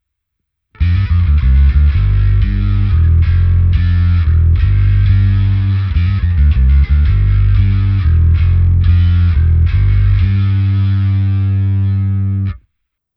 Pro srovnání, abyste věděli, jaký typ zkreslení vyznávám, připojuji další ukázku s mým milovaným preampem Darkglass Microtubes X Ultra.